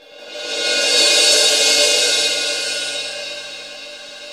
Index of /90_sSampleCDs/Roland - Rhythm Section/CYM_FX Cymbals 1/CYM_Cymbal FX
CYM MALLET0B.wav